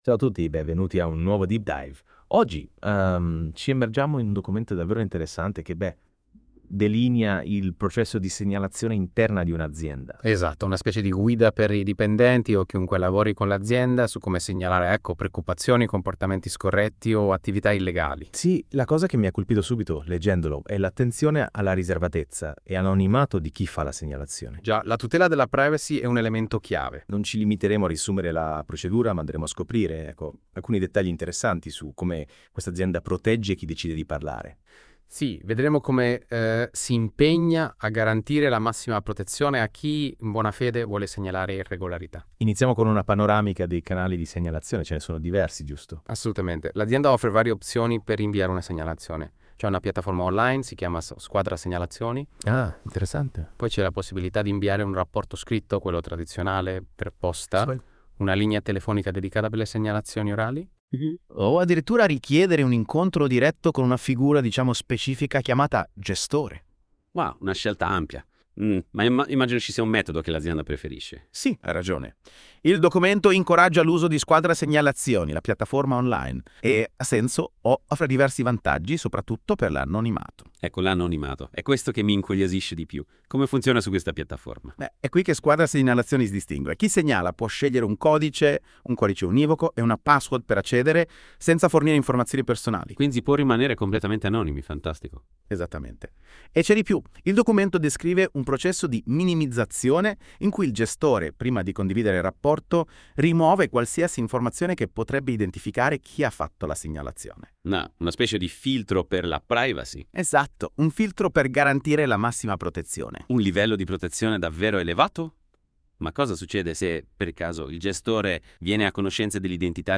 1_WHI_GoogleNB Conversazione - iltigliosrl
NotebookLM, nel dicembre 2024, generava le conversazioni ancora unicamente in lingua inglese ma ne permette la personalizzazione attraverso la quale è possibile ottenerle in italiano (anche se la pronuncia non è corretta).